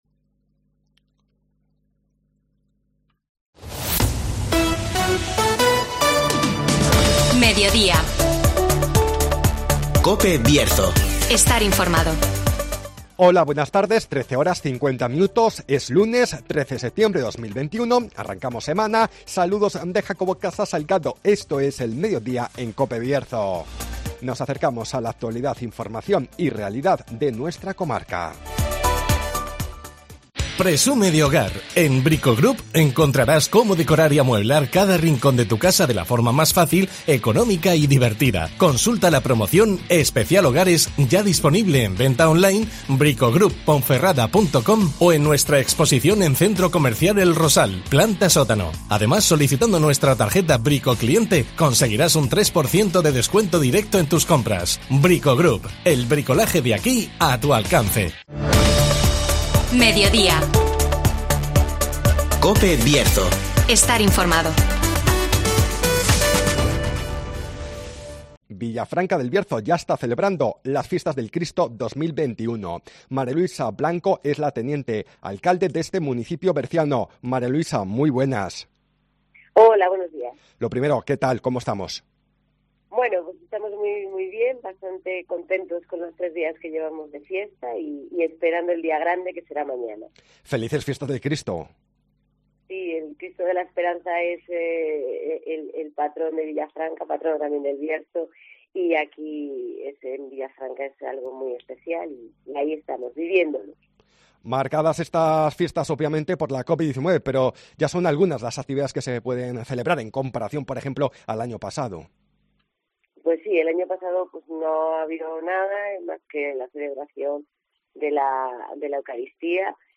Fiestas del Cristo de Villafranca del Bierzo (Entrevista